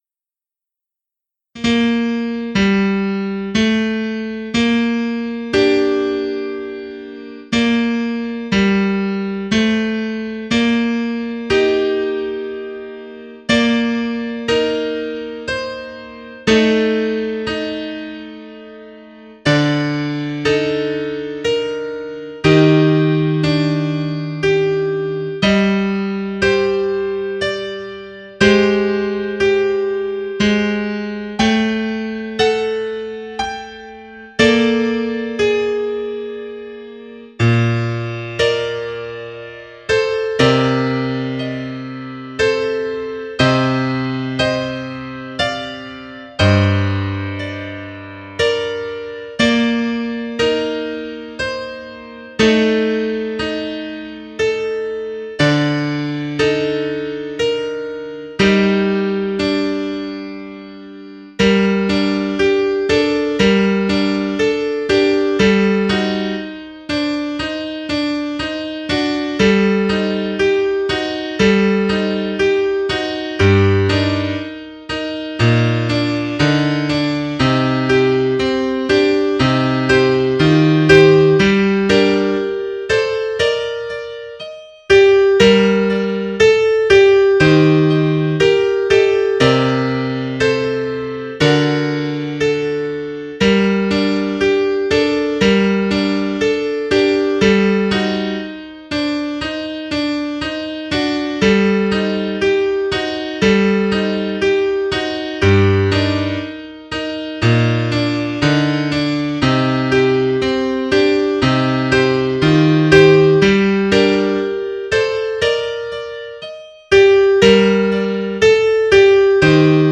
Oefenbestanden Jiddische liederen
Tsigajnerlid totaal (sopr+alt+bas)